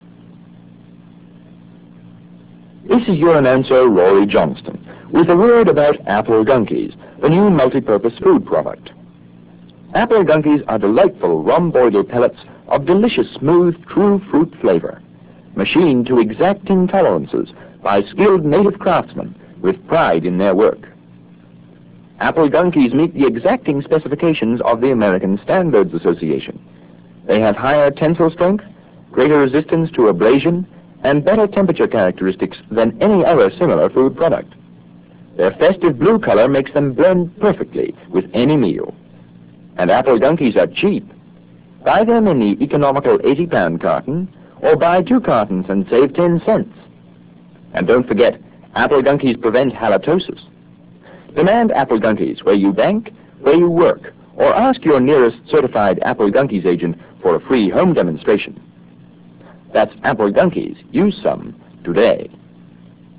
I decided it would be fun to run fake humorous commercials instead.
#1 was the very first Apple Gunkies ad ever to air.